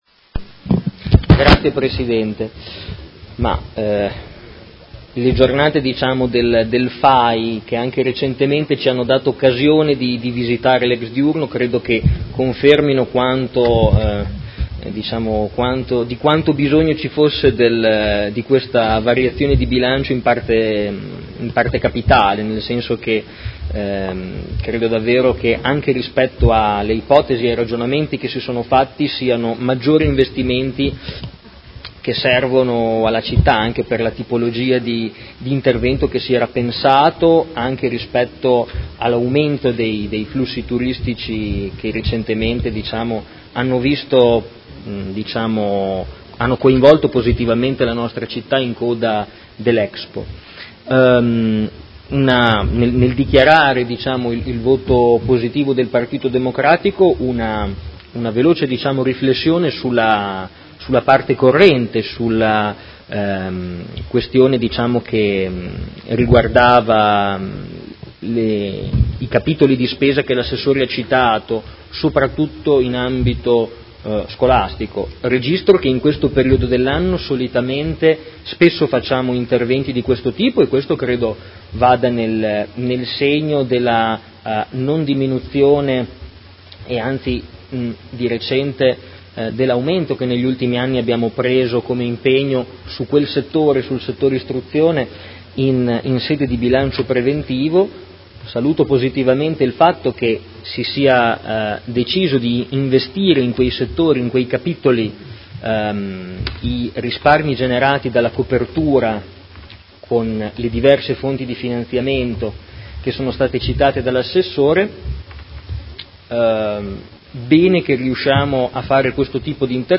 Seduta del 18/10/2018Dichiarazione di voto. Bilancio di Previsione 2018-2020 e Programma Triennale dei Lavori Pubblici 2018-2020: Variazione di Bilancio n. 5